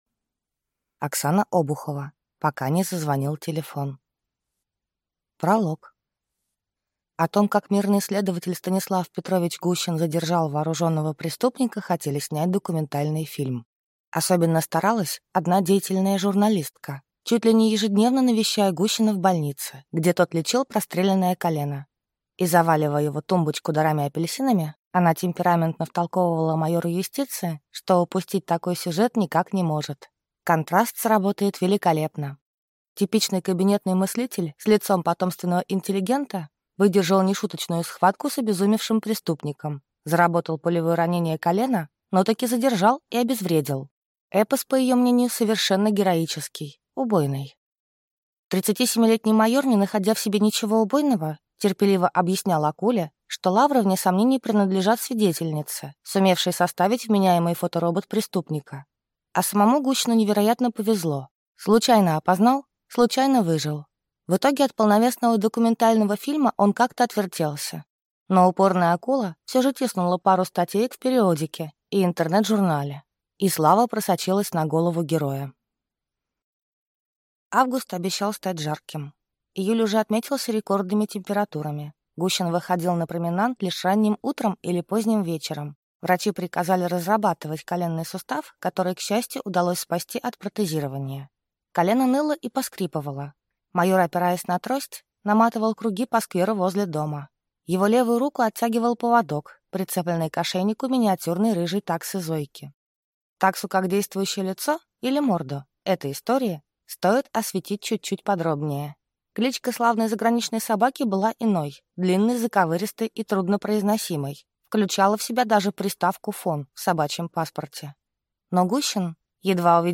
Аудиокнига Пока не зазвонил телефон | Библиотека аудиокниг